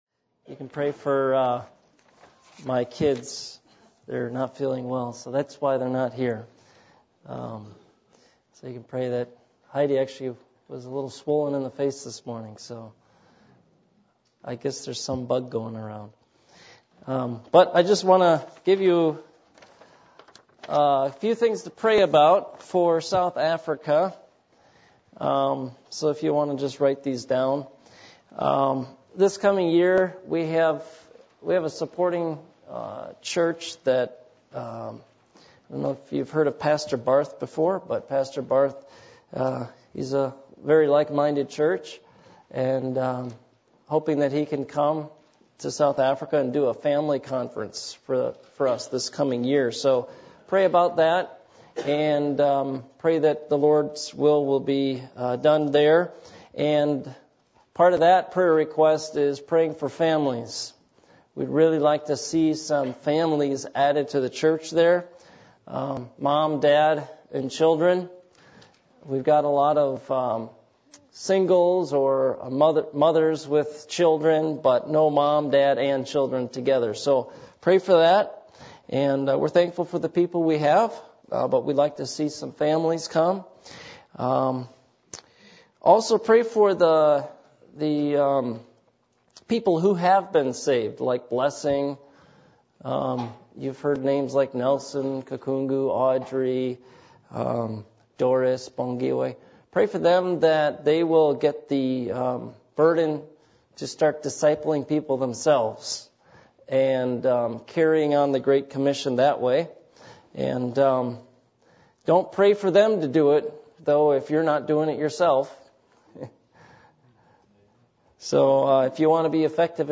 Passage: Psalm 5:1-12 Service Type: Midweek Meeting %todo_render% « Christmas Program Do You Know Jesus